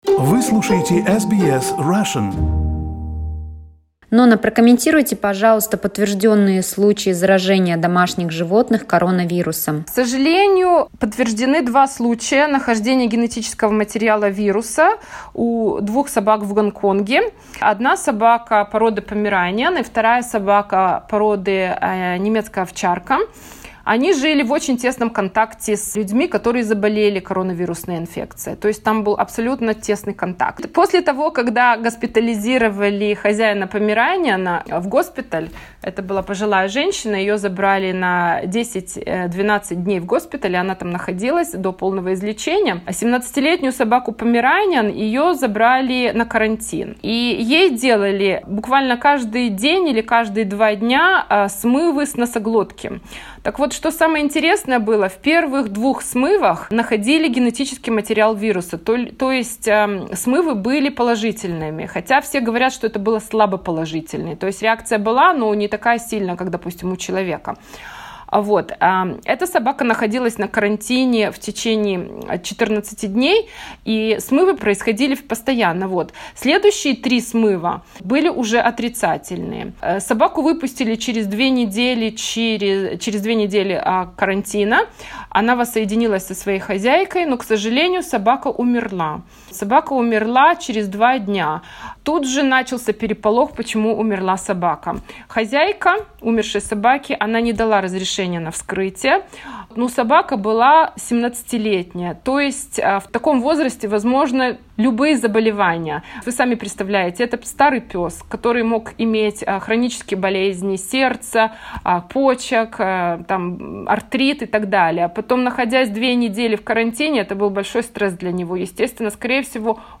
Interview with a veterinarian from Canberra